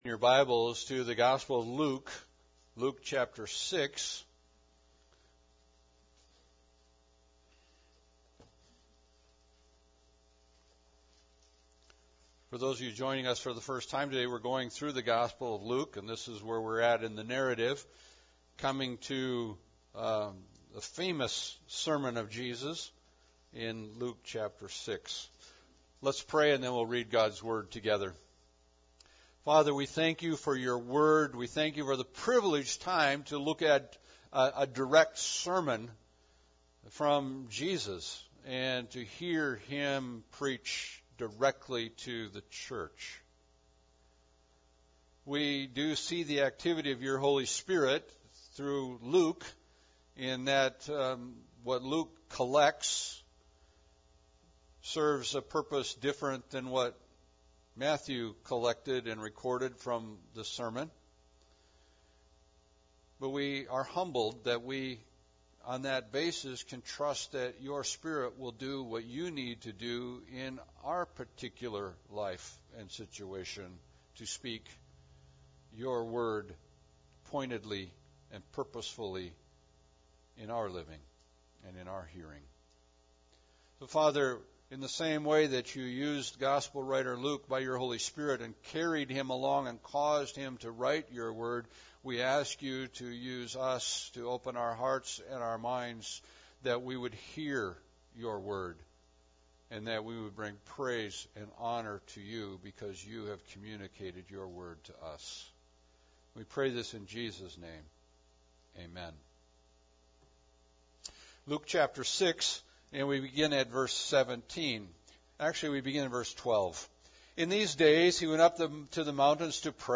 Passage: Luke 6:12-49 Service Type: Sunday Service